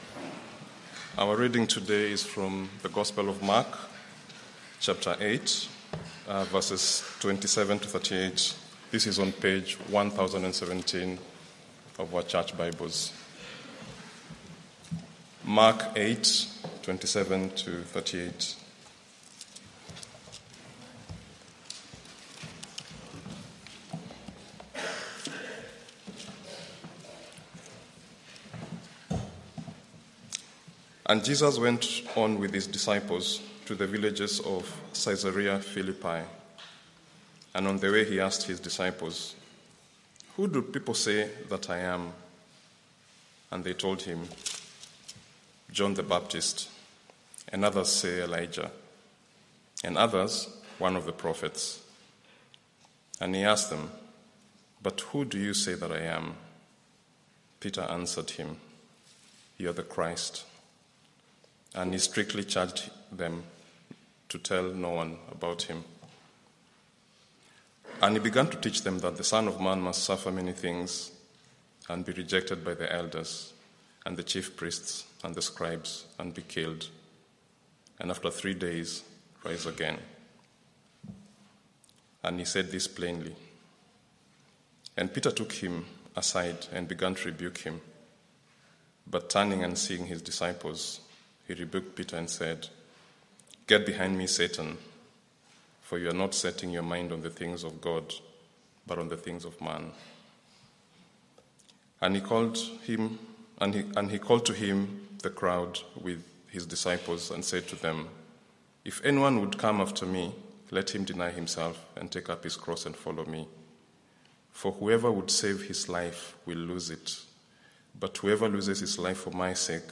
Media for Morning Meeting on Sun 02nd Mar 2025 10:30 Speaker
Sermon - Audio Only Search media library...